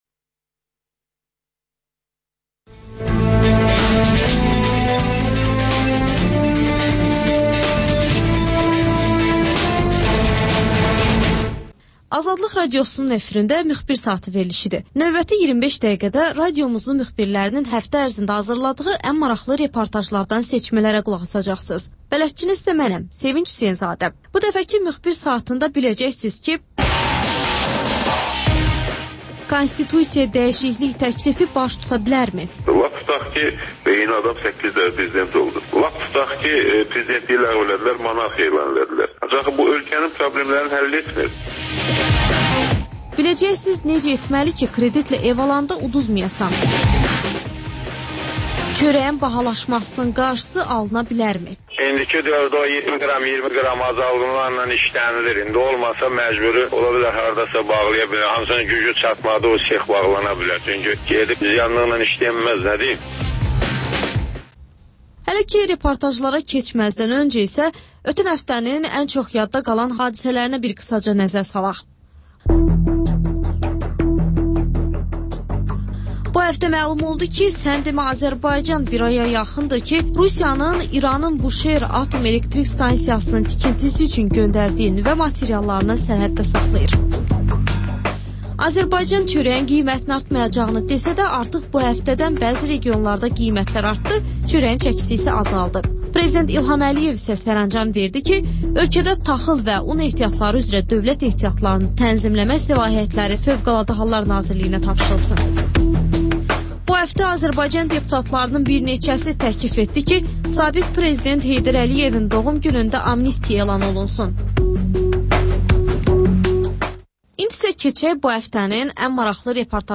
Müxbirlərimizin həftə ərzində hazırladıqları ən yaxşı reportajlardan ibarət paket (Təkrar)